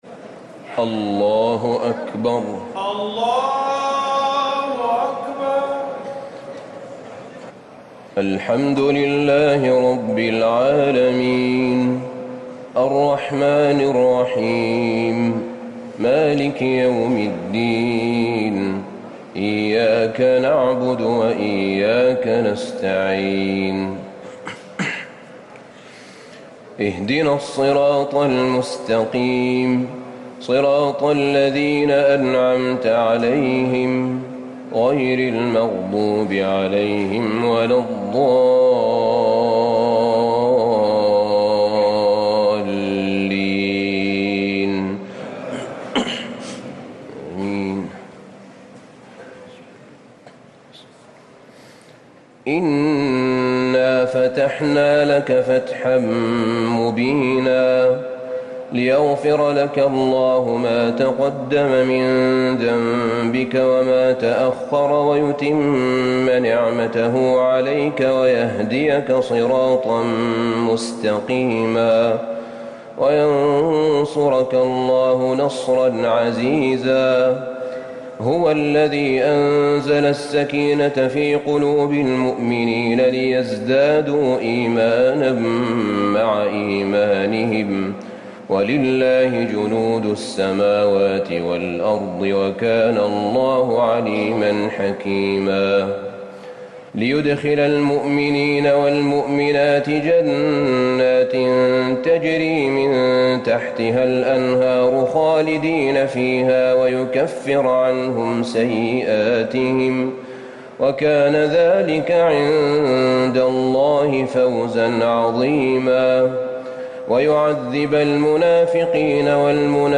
تراويح ٢٥ رمضان ١٤٤٠ من سورة الفتح الى الحجرات > تراويح الحرم النبوي عام 1440 🕌 > التراويح - تلاوات الحرمين